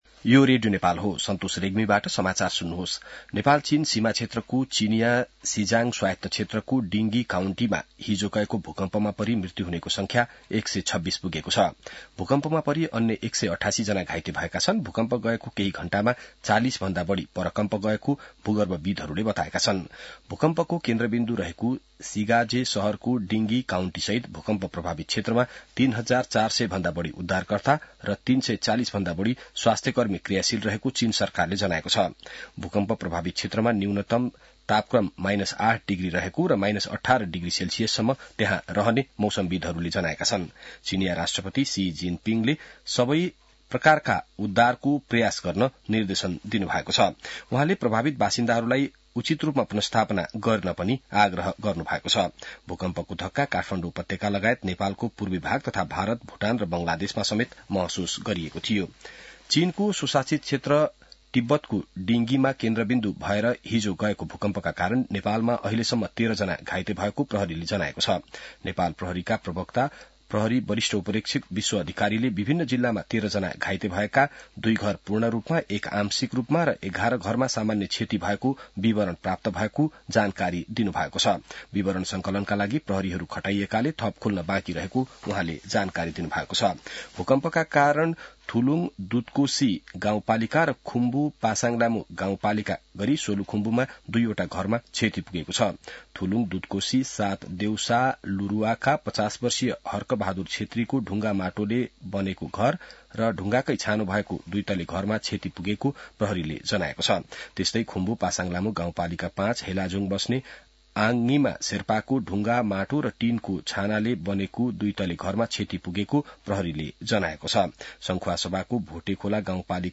बिहान ६ बजेको नेपाली समाचार : २५ पुष , २०८१